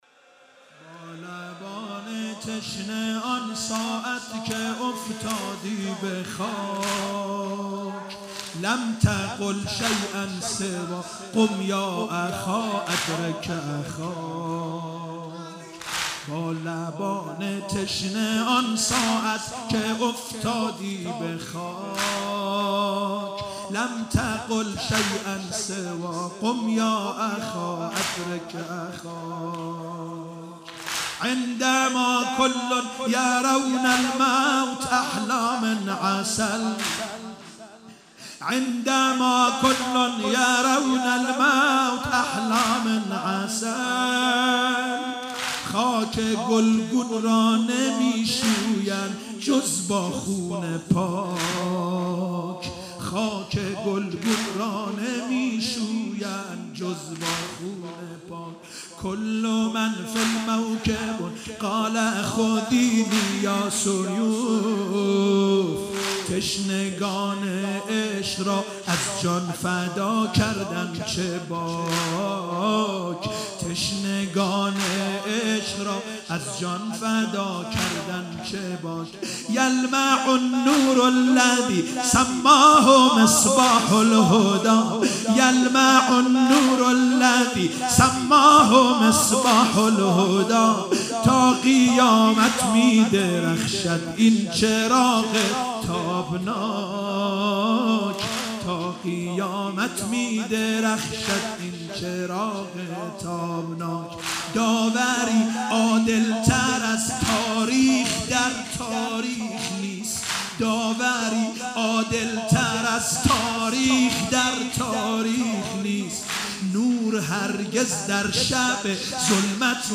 شب عاشورا محرم 96 - واحد - با لبان تشنه آن ساعتی که افتادی به خاک